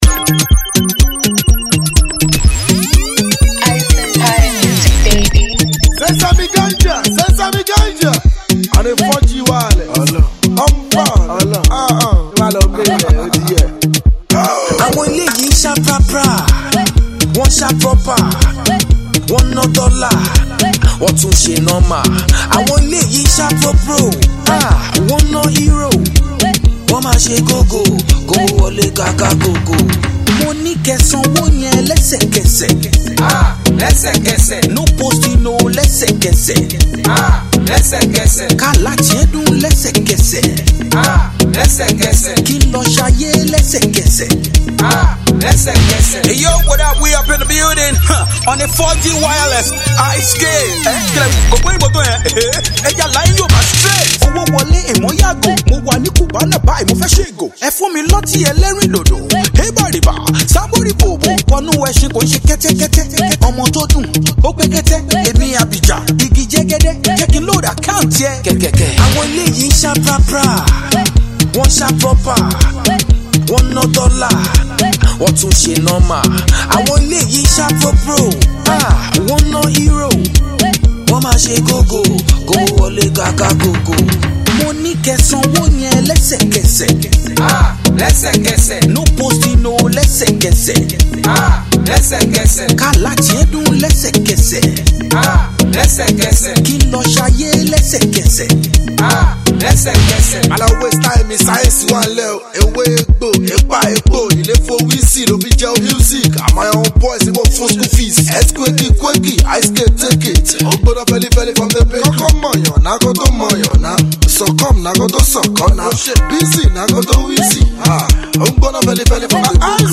an afro pop fusion with the trending shaku shaku dance
street hop rap style
street hop